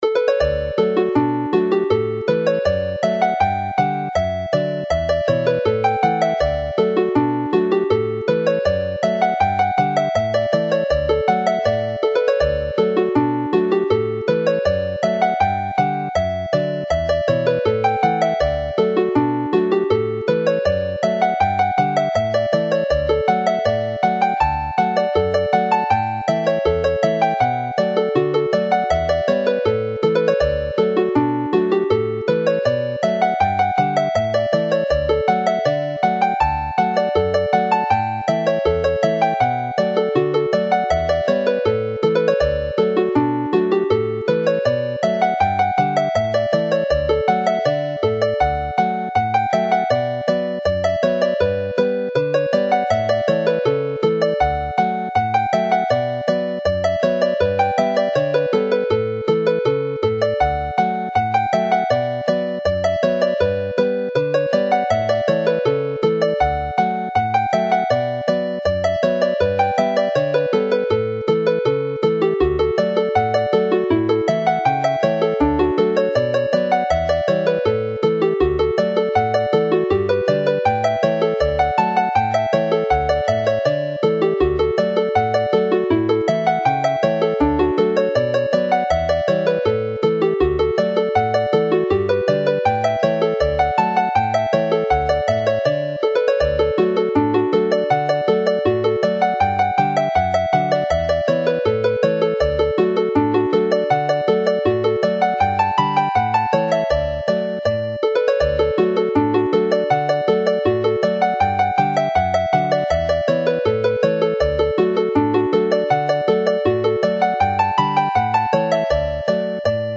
This is a lovely set of hornpipes which play well on the whistle with an experienced player, offer more of a challenge to fiddle players but run with ease on the harp as the fingers fall naturally to play the arpeggios (from the Italian name for harp!).